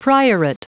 Prononciation du mot priorate en anglais (fichier audio)
Prononciation du mot : priorate